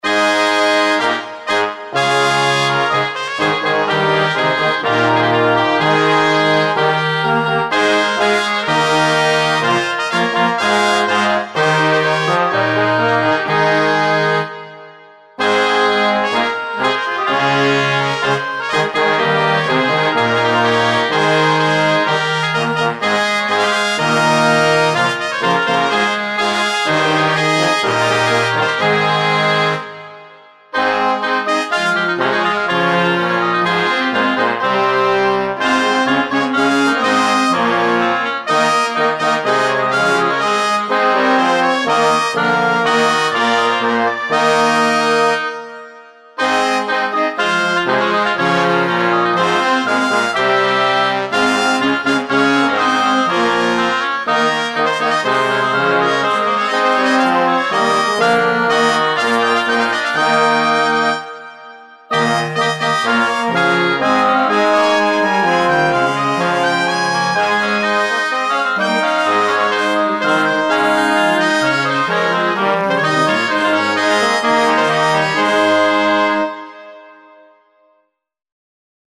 Andante [80-90] amour - trompette - cour - feodal - medieval